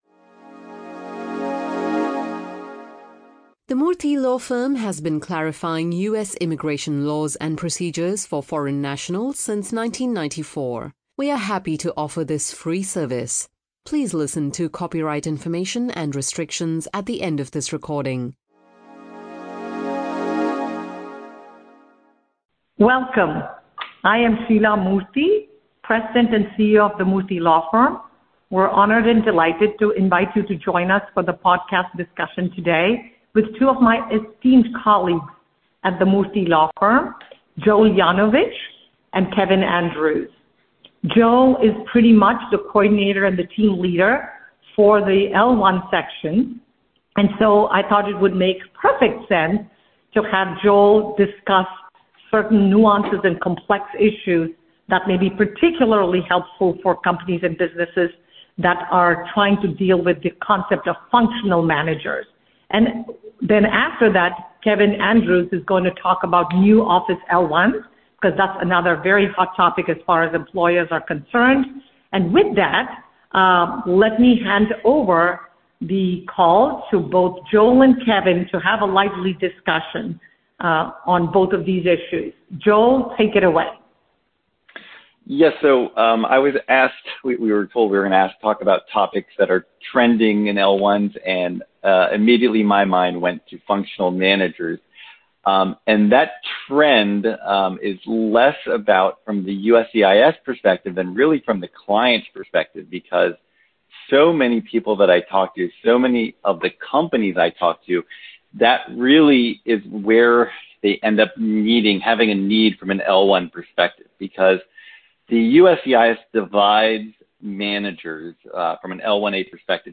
Trending issues in L1A/L1B filings are under discussion among Murthy Law Firm attorneys in this Employer Podcast. Topics addressed include L1A functional managers, work location changes, and proving specialized knowledge.